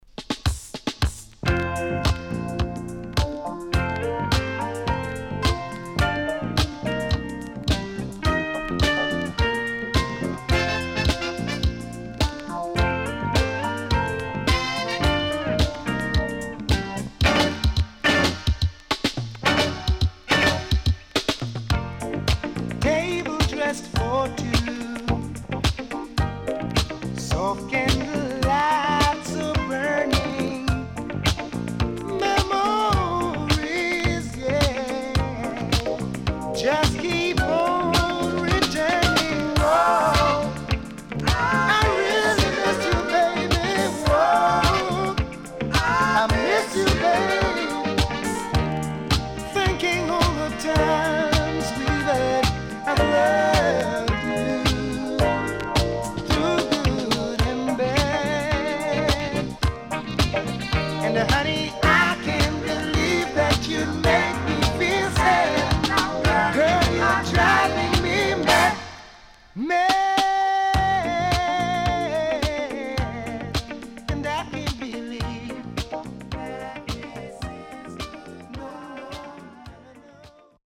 SIDE B:少しチリノイズ入ります。